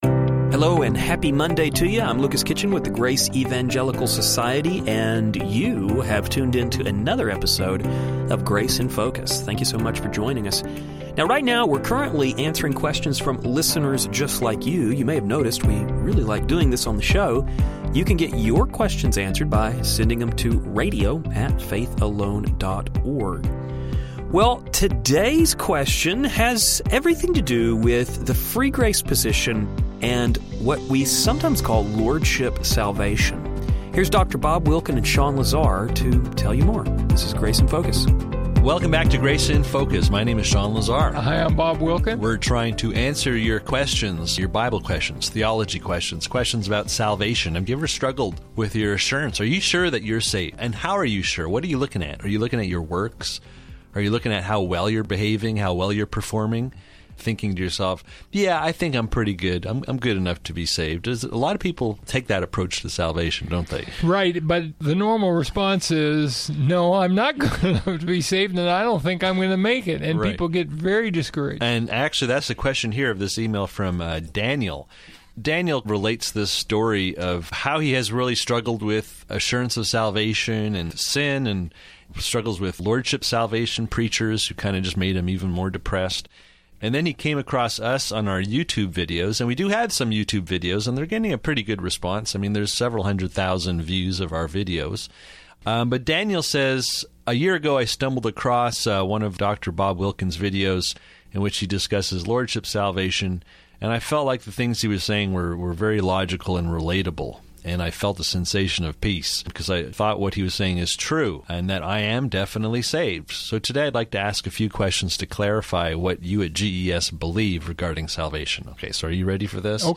Grace in Focus Radio